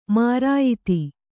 ♪ mārāyiti